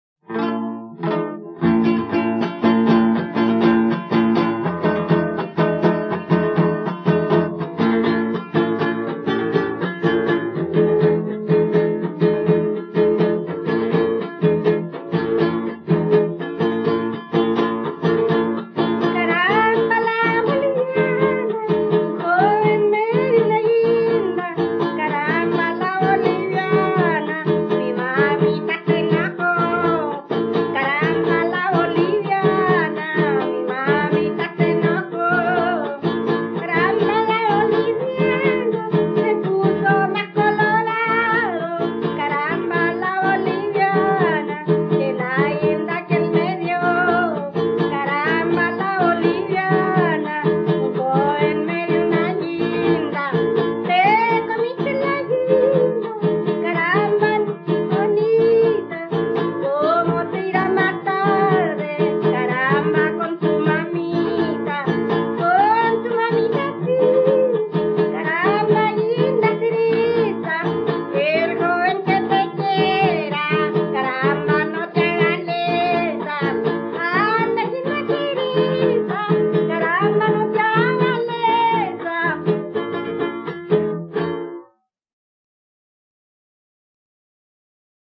Música tradicional
Folklore
Cueca